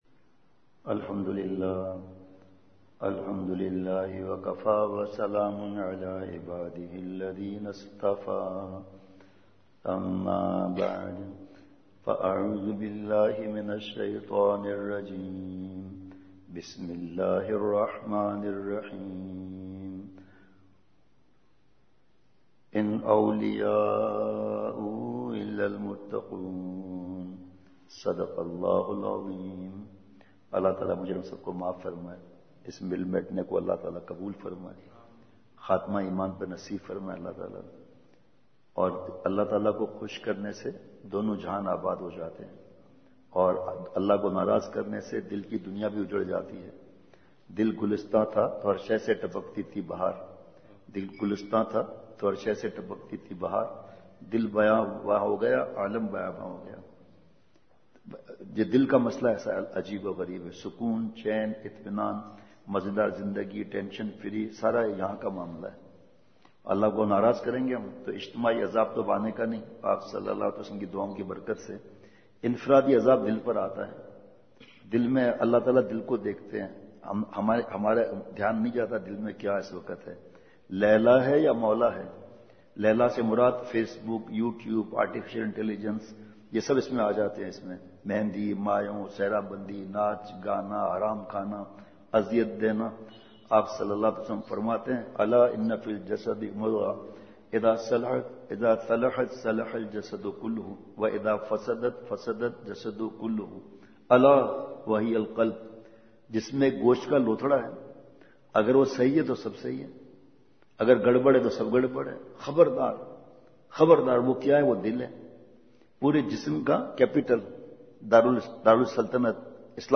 *بمقام:۔جامع مسجد محمد سرگودھا گجرات روڈ*
*نمبر(13):بیان*